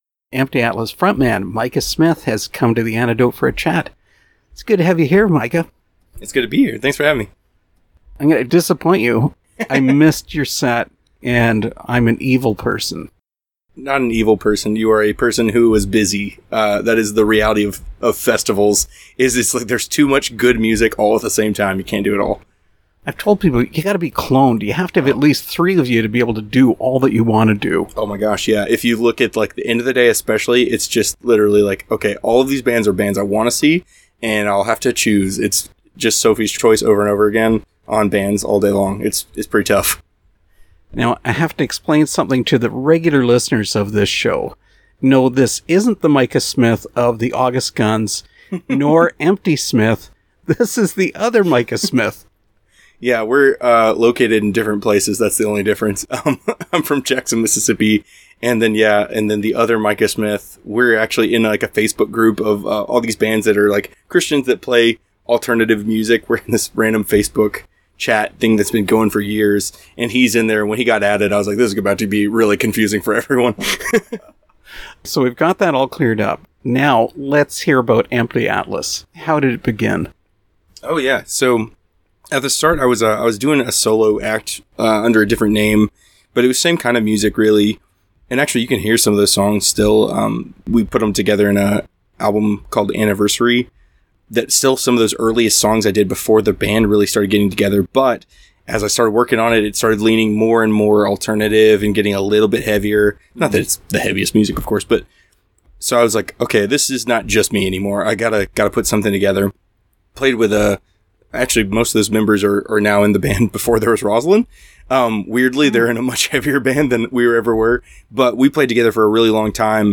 Interview with Empty Atlas